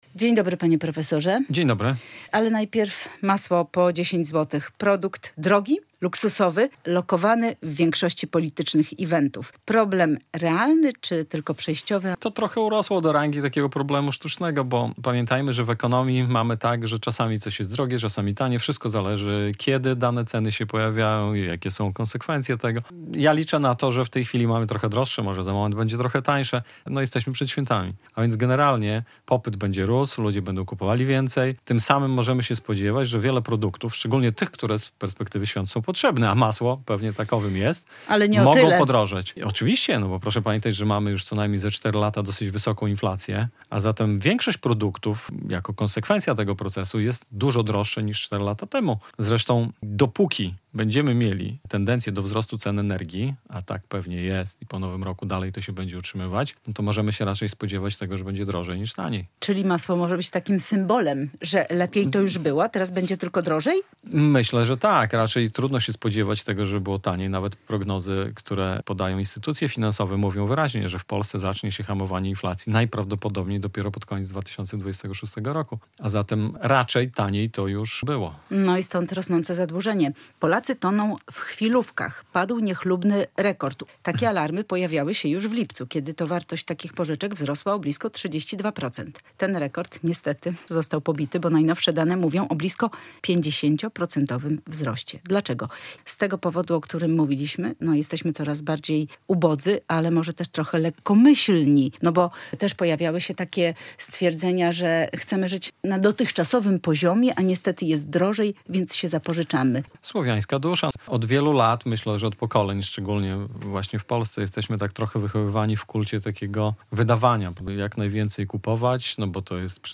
Radio Białystok | Gość